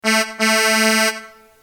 truck-horn.mp3